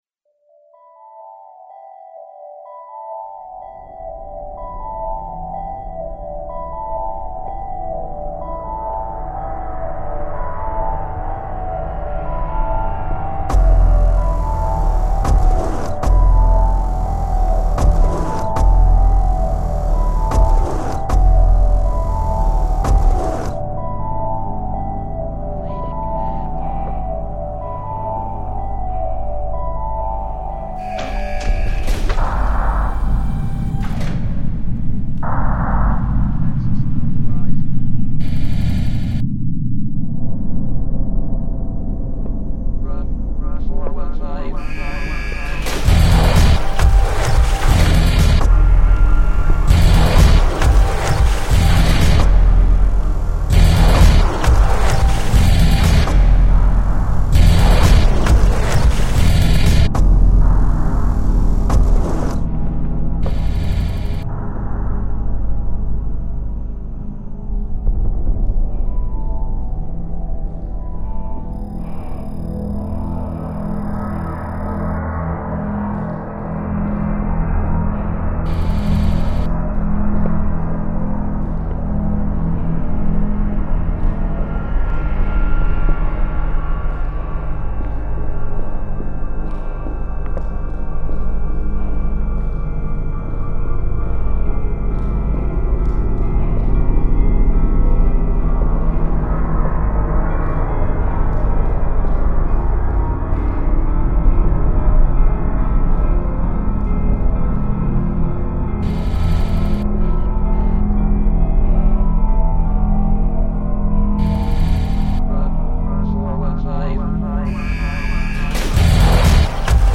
Dark Heavy Tech